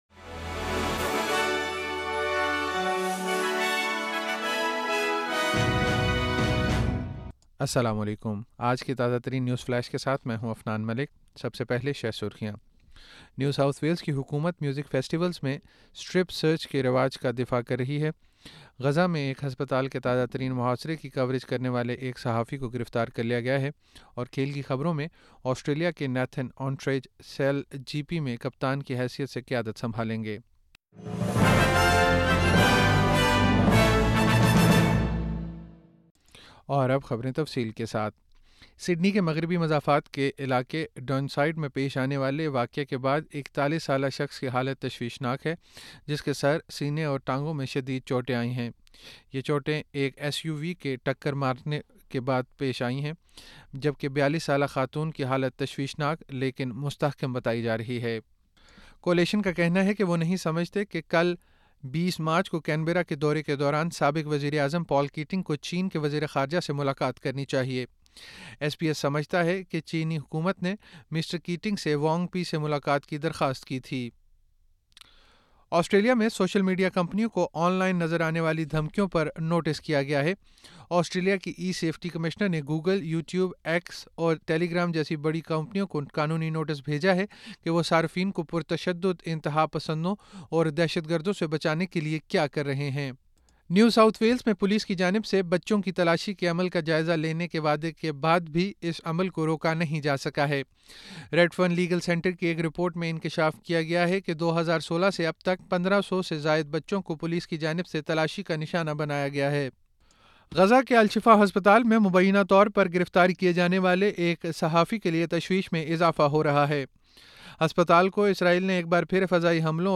نیوز فلیش 19 مارچ 2024: نیو ساؤتھ ویلز کی حکومت میوزک فیسٹیولز میں سٹرپ سرچ کے رواج کا دفاع